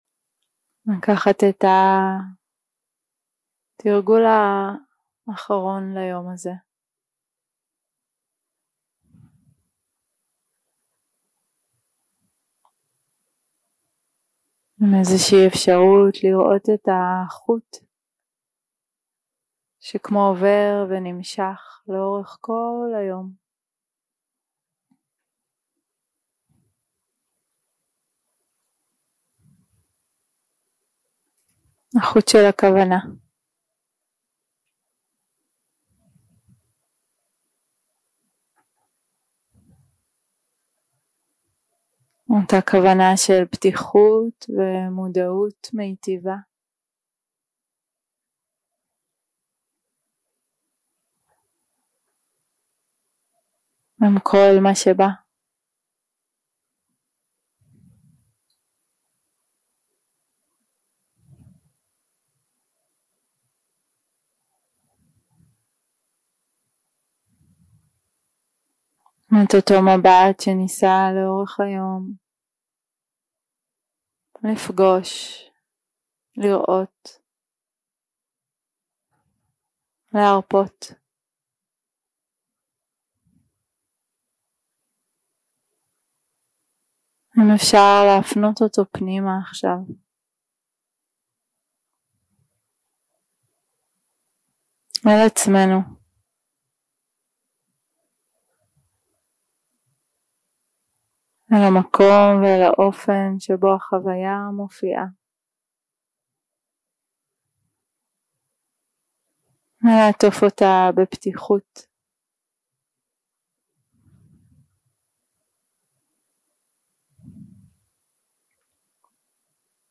day 2 - recording 6 - Late Evening - Meta Chanting
Dharma type: Guided meditation שפת ההקלטה